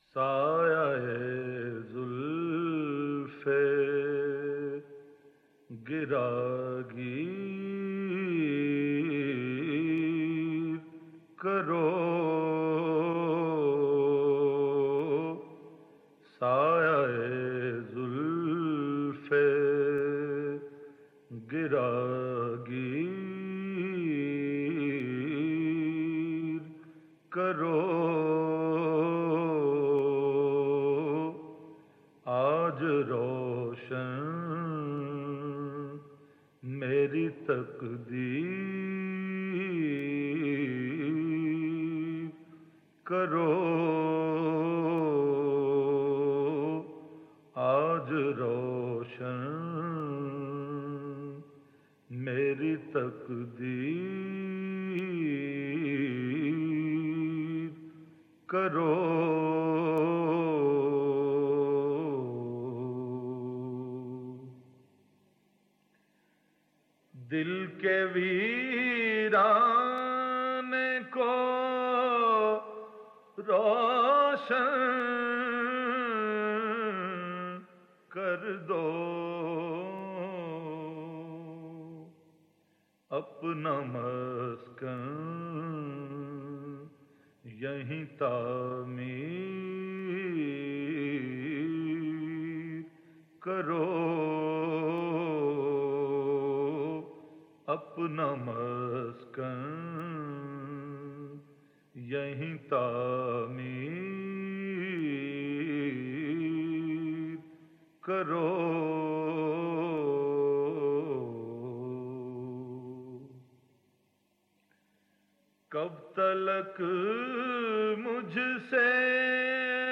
نعت رسول مقبول ص